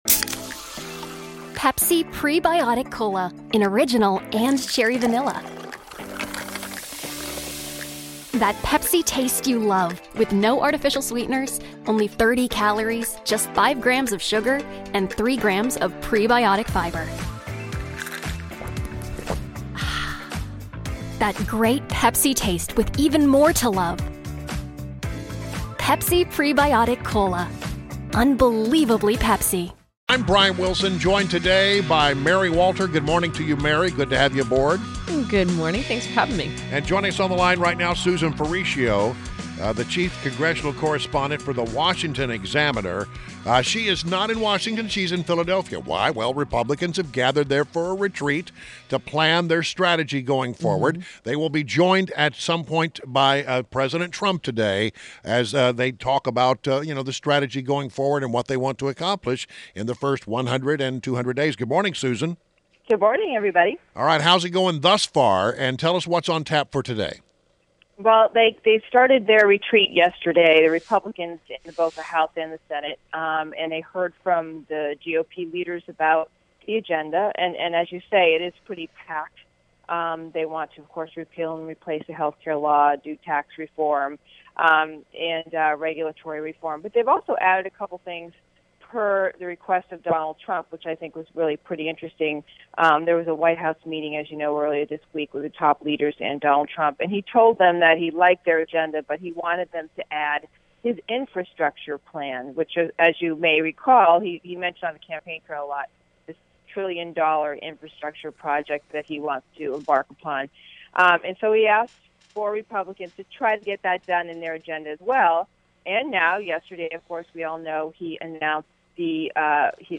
WMAL Interview
INTERVIEW